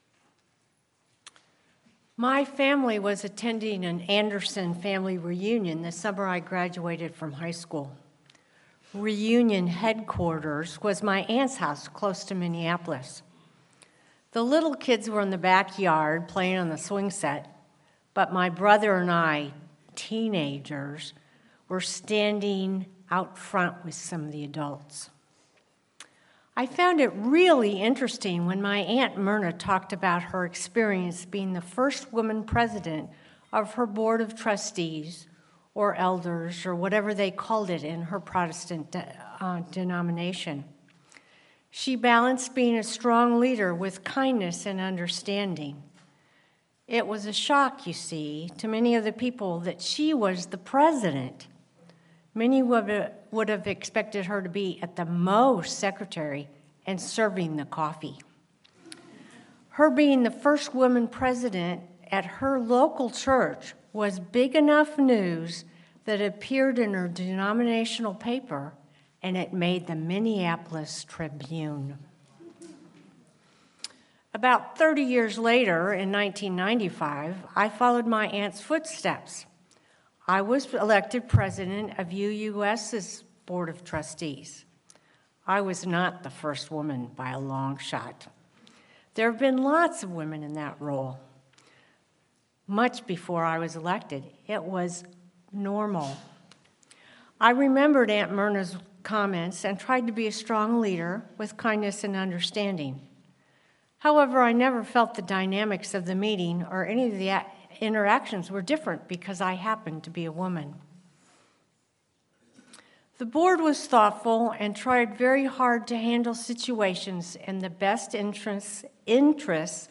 Sermon-Presence-Power-and-Possibility.mp3